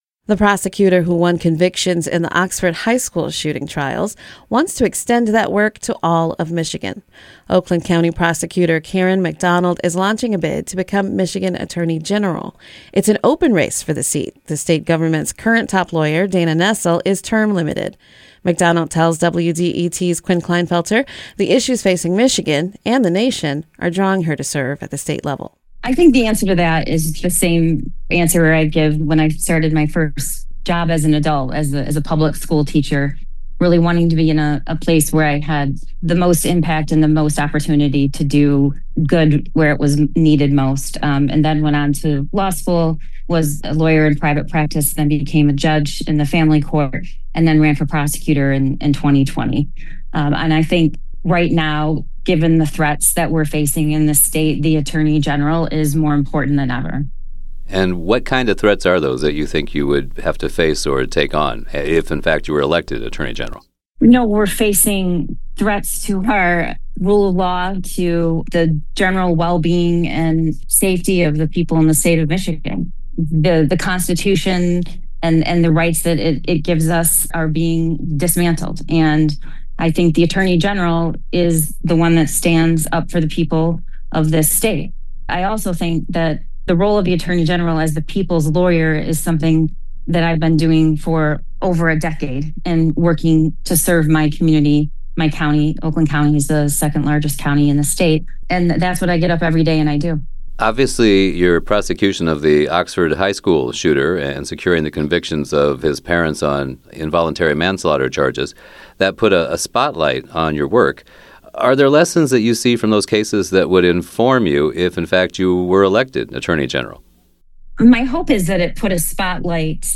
The following interview has been edited for clarity and length.